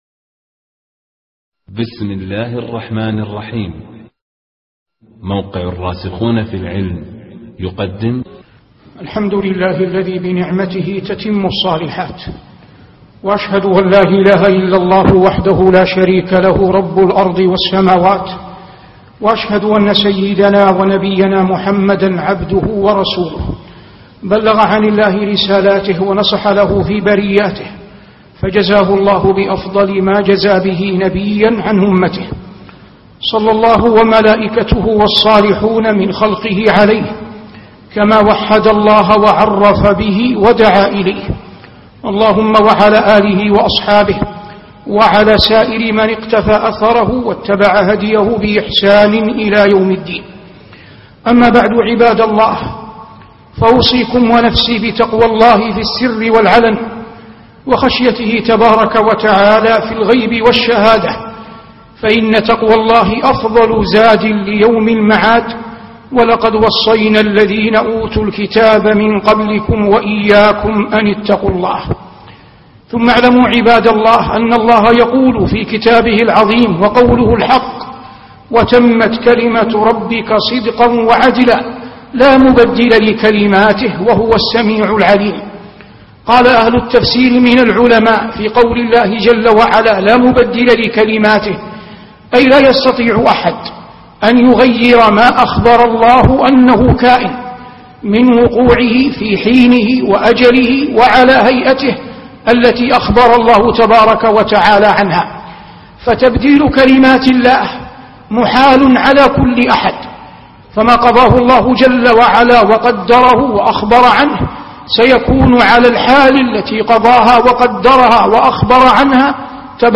وتمت كلمة ربك- خطب الجمعة